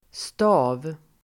Uttal: [sta:v]